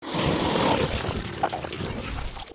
zombie_eat_2.ogg